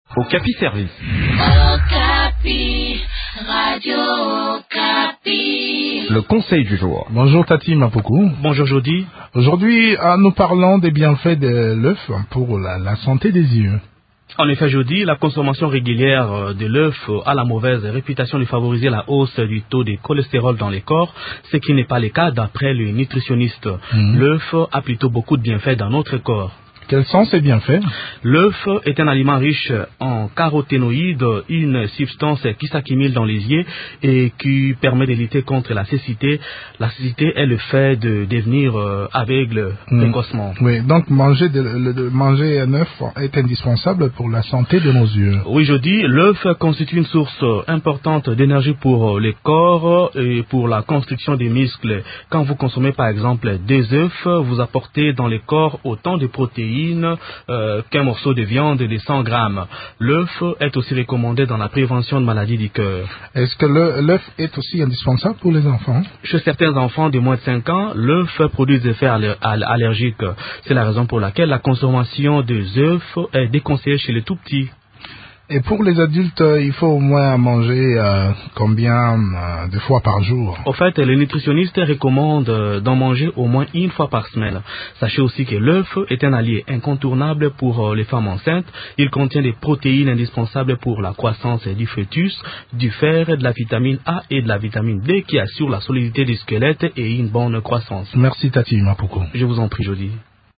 D’autres précisions sur les vertus de l’œuf dans cet entretien